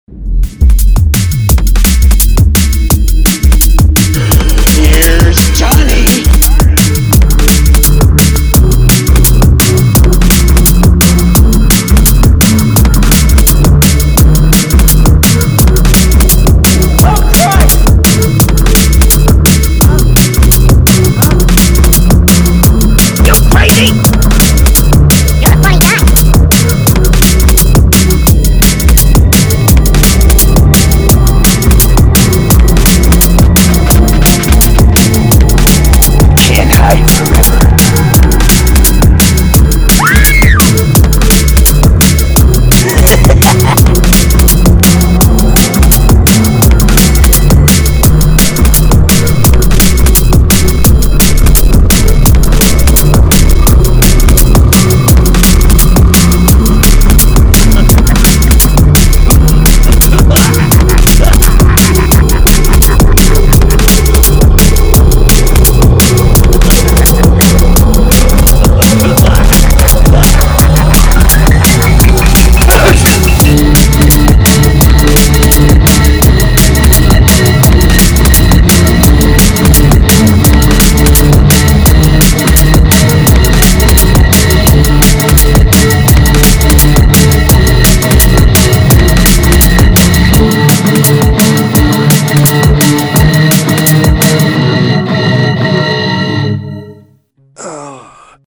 �������, ������ �������� ��������� �������� ��������� � ����� experimental illbient d'n'b guitar:������ ����������� ������ �� �...